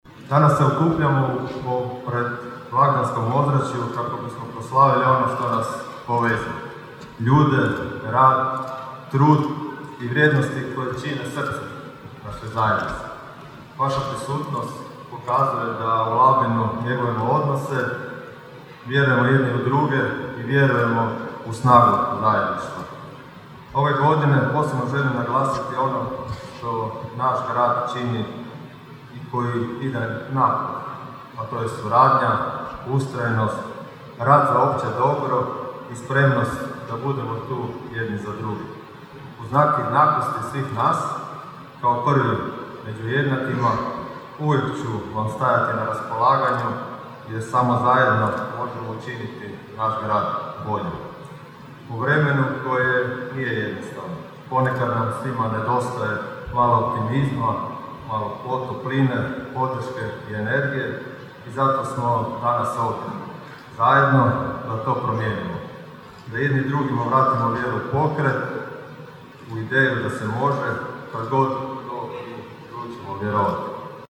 Grad Labin održao je predblagdansko druženje Snaga zajedništva – Labin pred blagdane uz gospodarstvenike, kulturnjake, sportaše i civilno društvo.
U maloj dvorani sportskog centra Franko Mileta sve prisutne pozdravio je labinski gradonačelnik Donald Blašković, kazavši kako je ovaj susret posvećen zajedništvu, uspjehu i inspiraciji: (